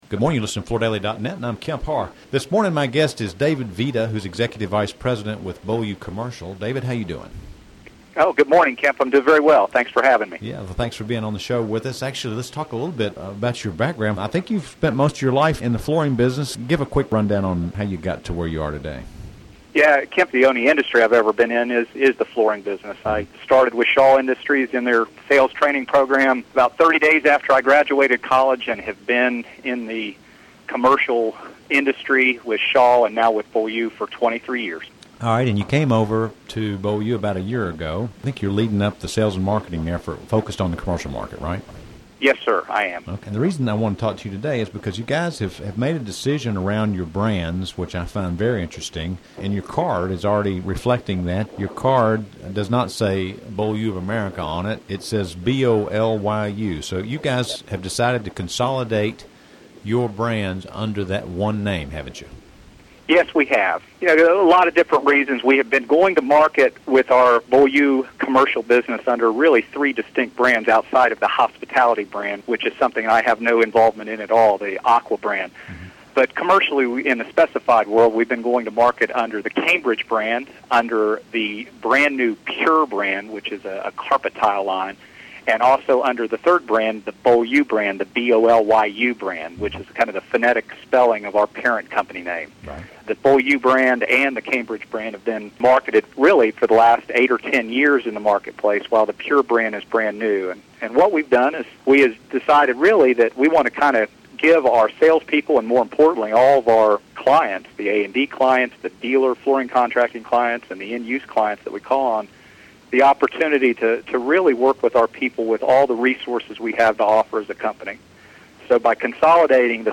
Listen to the interview to hear the strategy behind this decision and their contract commercial focus moving forward.